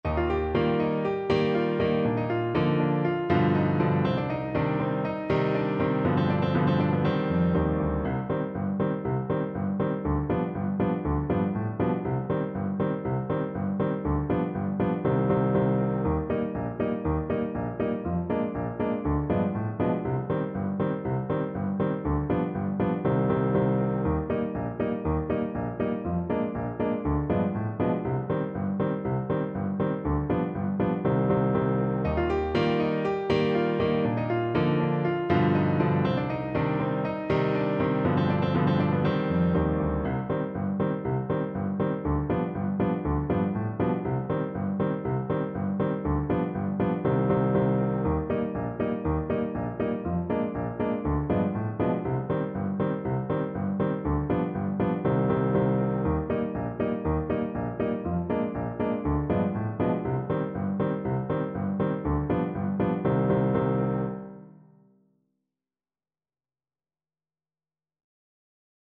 Play (or use space bar on your keyboard) Pause Music Playalong - Piano Accompaniment Playalong Band Accompaniment not yet available transpose reset tempo print settings full screen
4/4 (View more 4/4 Music)
C minor (Sounding Pitch) D minor (Trumpet in Bb) (View more C minor Music for Trumpet )
Allegro (View more music marked Allegro)